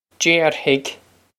Déarfaidh Jayr-hig
Pronunciation for how to say
Jayr-hig
This is an approximate phonetic pronunciation of the phrase.